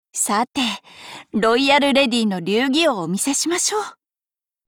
Cv-20403_battlewarcry_4.mp3 （MP3音频文件，总共长4.7秒，码率320 kbps，文件大小：183 KB）
贡献 ） 协议：Copyright，人物： 碧蓝航线:胡德语音 您不可以覆盖此文件。